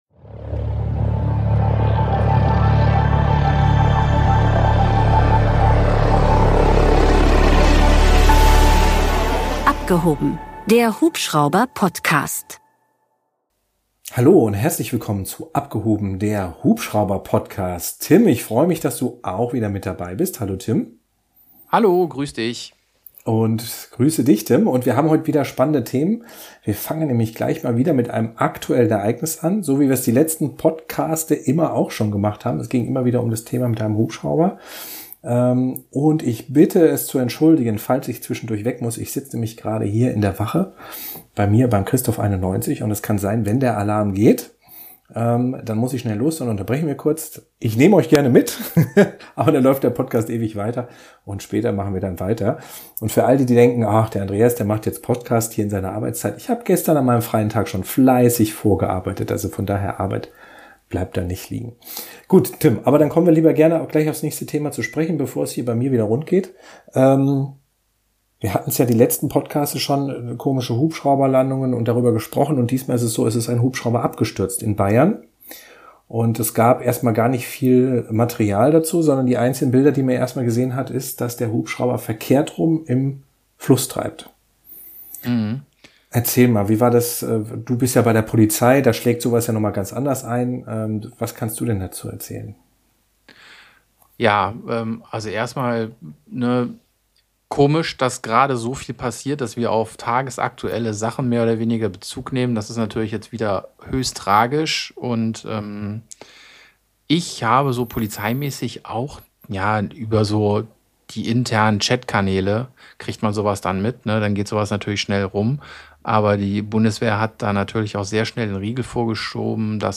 Play Rate Listened List Bookmark Get this podcast via API From The Podcast Zwei totale Hubschrauber- und Luftfahrtenthusiasten sprechen über alles, was mit der Fliegerei zu tun hat.